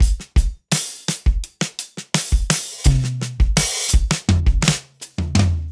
Index of /DESN275/loops/Loop Set - Smoke Signal - Live Trip Hop Drums/Loops
Bounce_84_AcousticDrumFill.wav